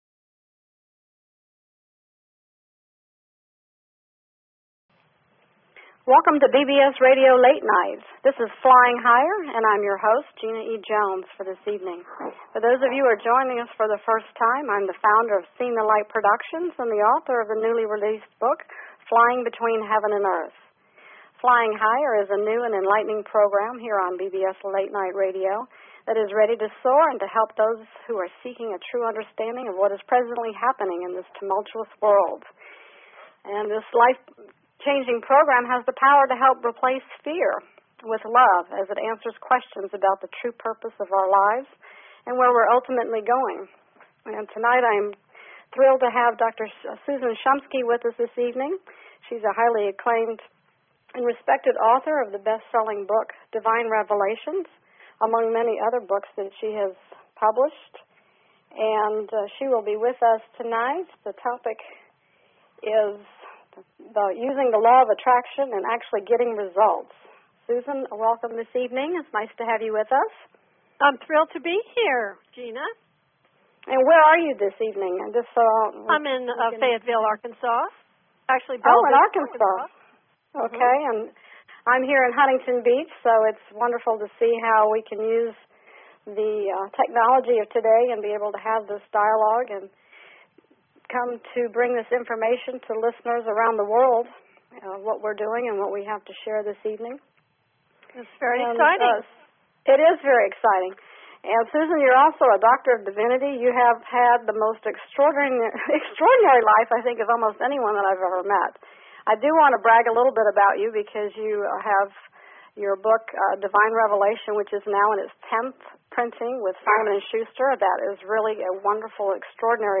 Talk Show Episode, Audio Podcast, Flying_Higher and Courtesy of BBS Radio on , show guests , about , categorized as